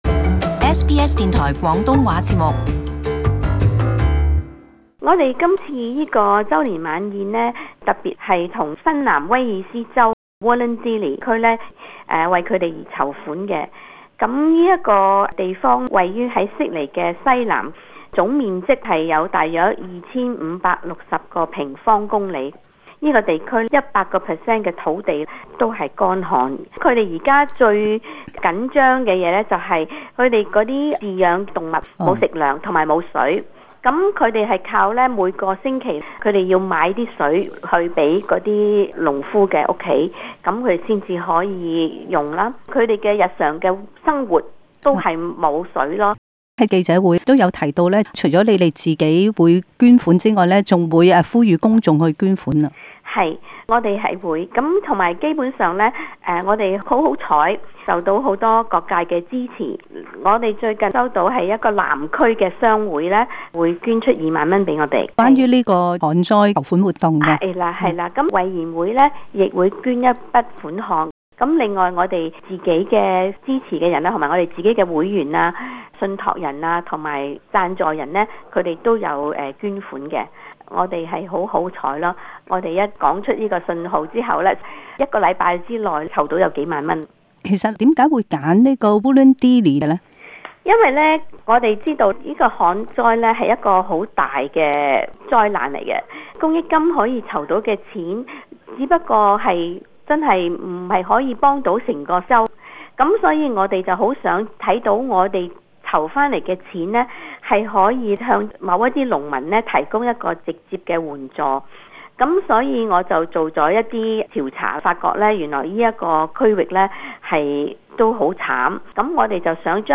【社區專訪】旱災籌款